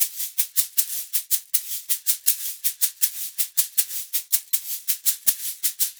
80 SHAK 02.wav